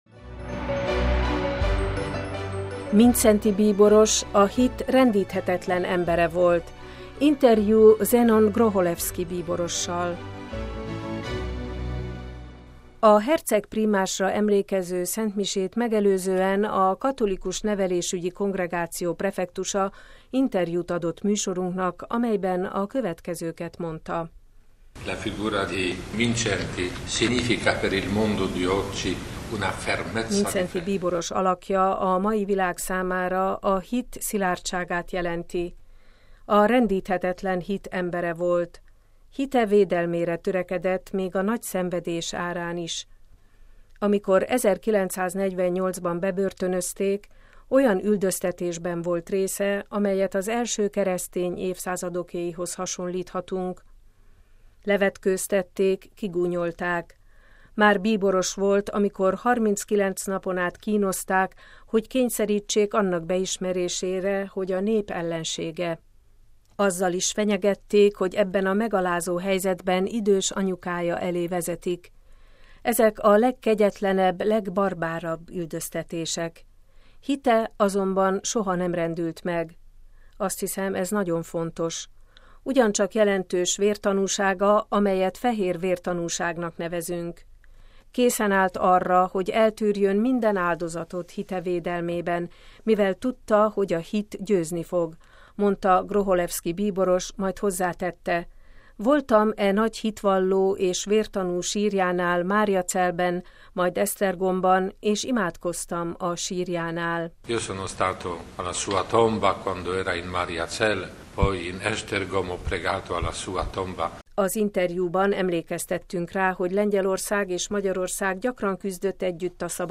„Mindszenty bíboros a hit rendíthetetlen embere volt” – interjú Zenon Grocholewski bíborossal
A hercegprímásra emlékező szentmisét megelőzően a Katolikus Nevelésügyi Kongregáció prefektusa interjút adott műsorunknak, amelyben a következőket mondta: